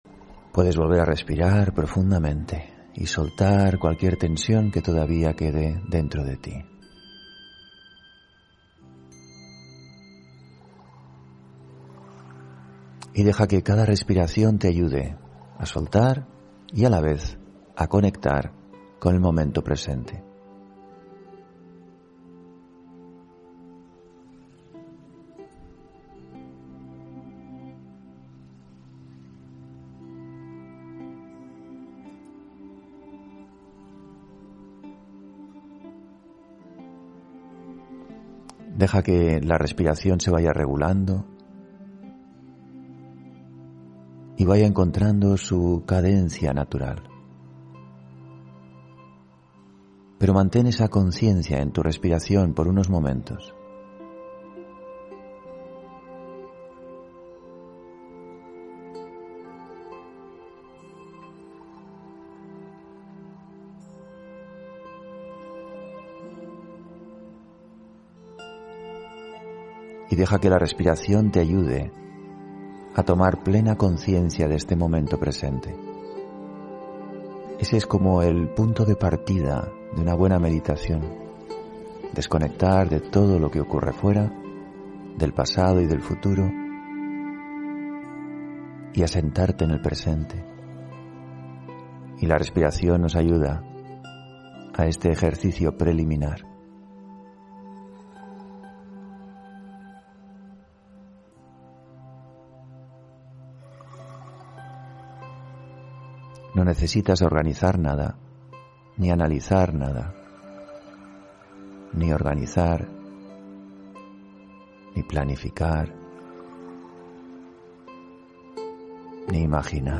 Meditación de la mañana: La conciencia del alma